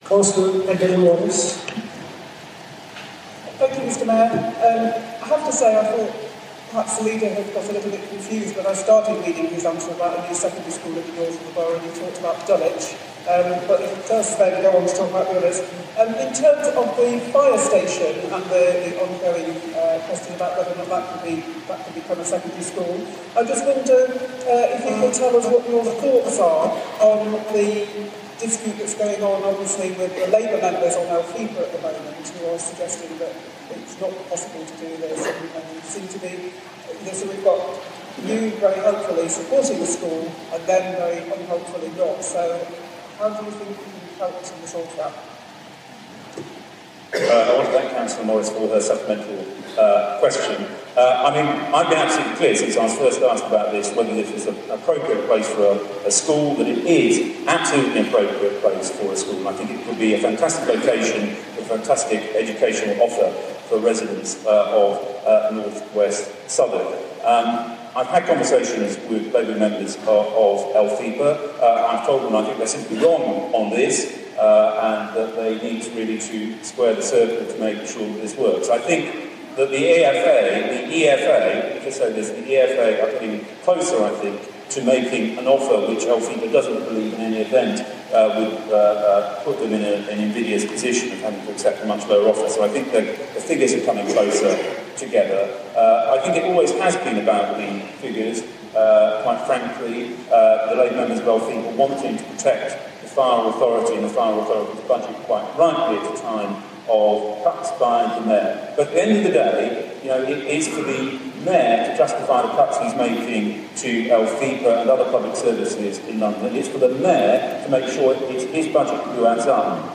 25 Feb 15: Cllr Peter John answers Q re Southwark Fire Station
Question by Cllr Adele Morris